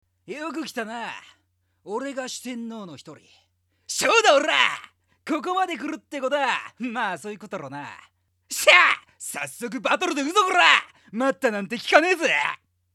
口調は荒いが、言ってる事は優しかったりする。
【イメージボイス】